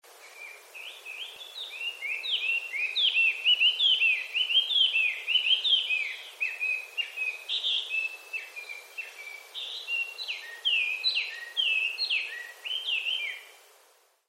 さえずりは大きく色々なバリエーションを持っているので面白い。
ガビチョウのさえずり